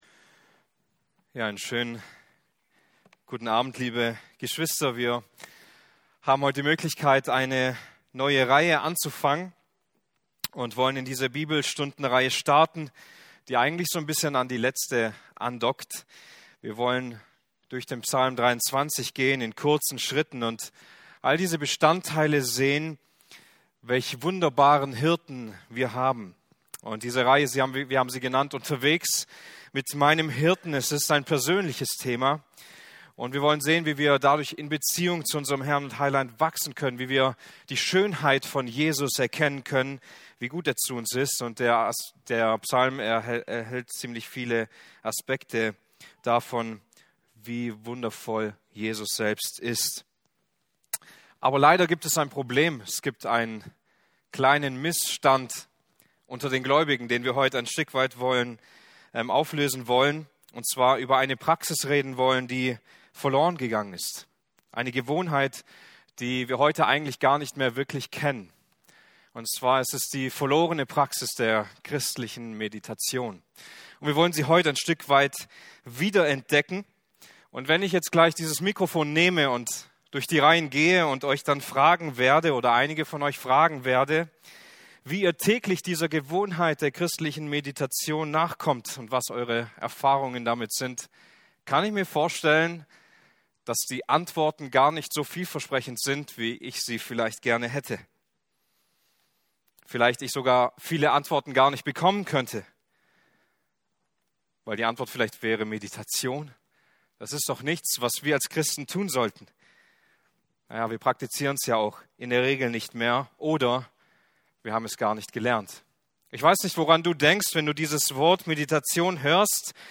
Dienstart: Bibelstunden